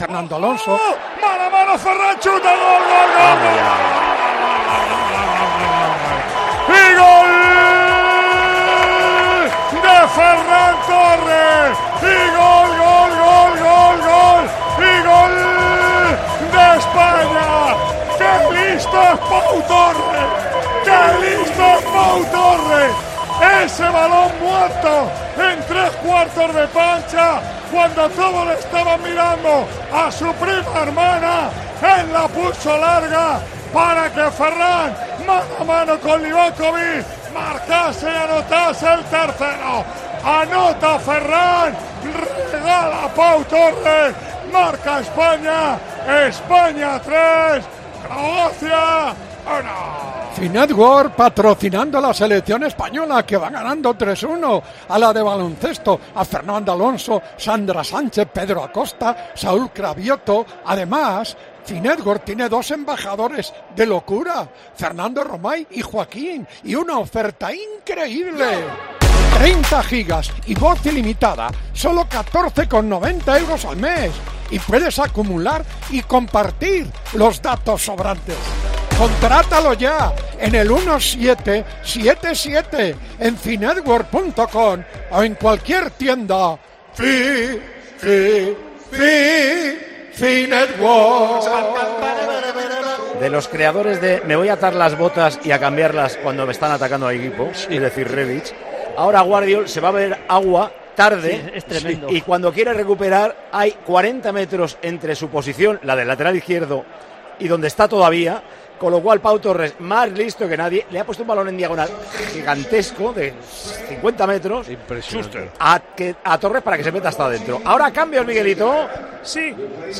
ASÍ NARRÓ MANOLO LAMA LOS GOLES DE CROACIA, 5 - ESPAÑA, 3